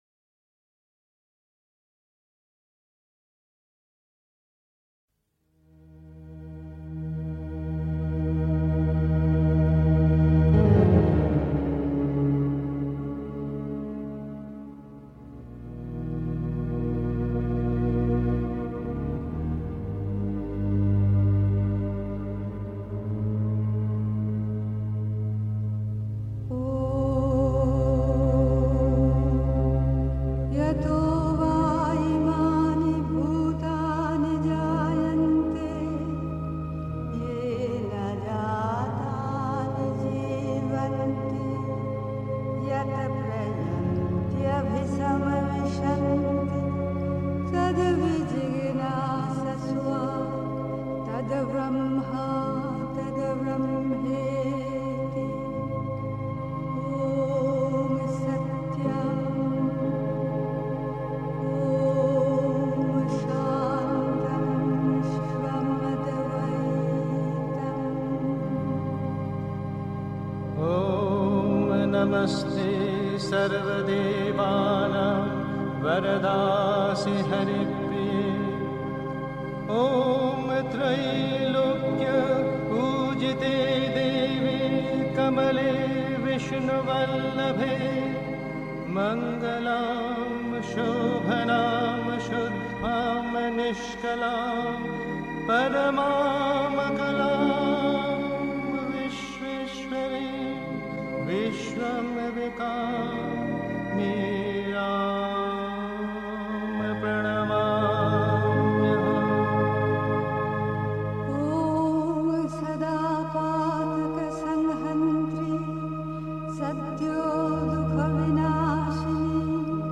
Pondicherry. 2. Nur das Göttliche ist wirklich (Die Mutter, Weisse Rosen, 8. April 1958) 3. Zwölf Minuten Stille.